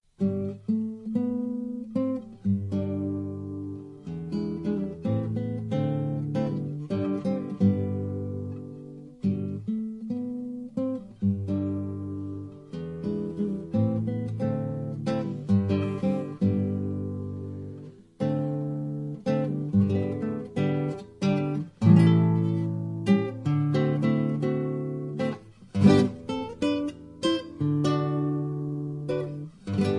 Traditional Polish Christmas Carols on classical guitar
(No Singing).